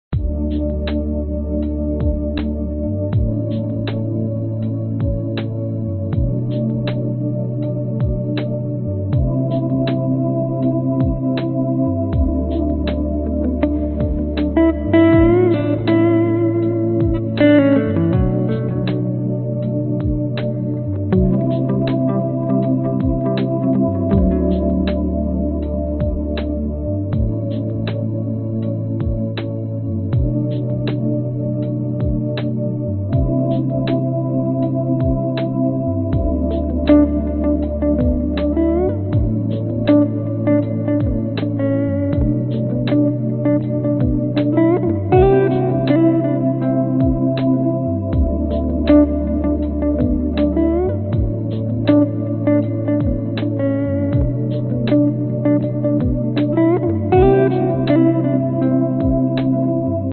描述：这是我正在做的一个非常悠闲的循环。我设法在上面加了一点电吉他的旋律，然后我就遇到了作曲家的障碍 :)
Tag: 慢节奏 吉他 合成器